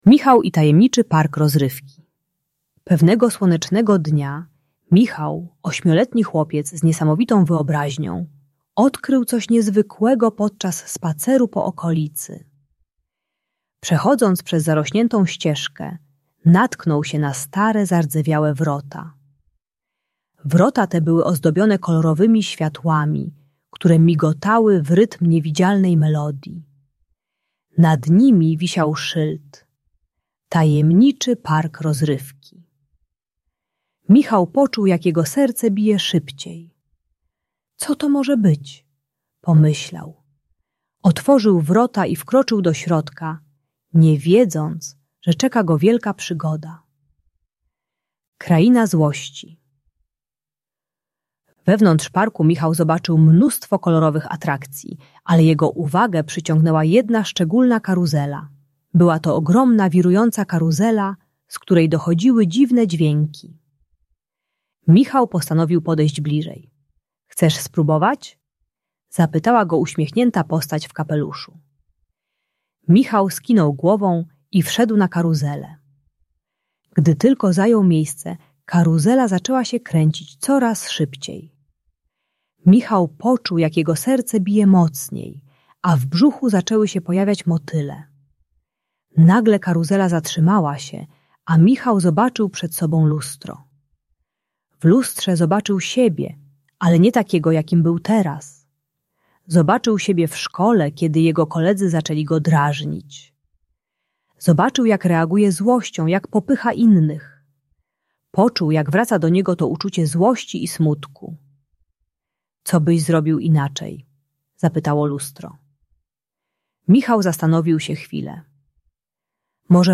Historia Michała w Tajemniczym Parku Rozrywki - Bunt i wybuchy złości | Audiobajka